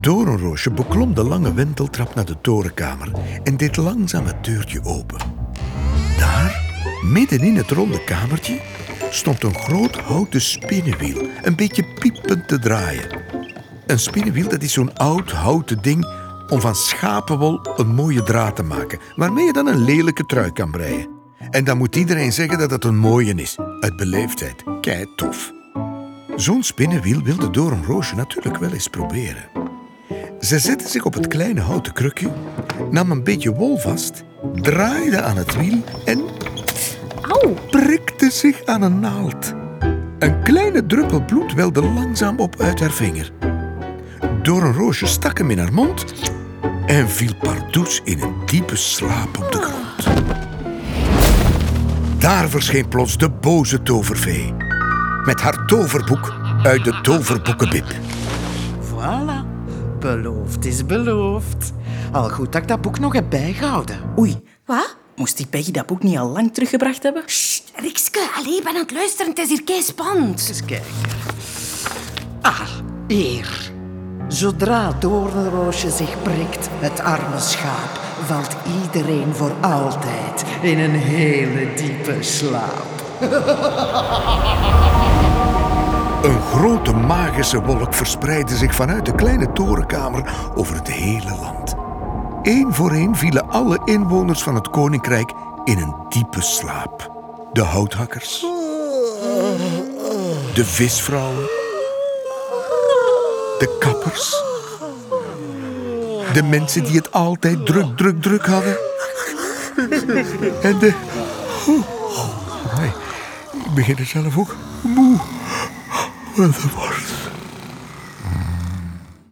Tekst (hoorspel en boek): Koen Van Deun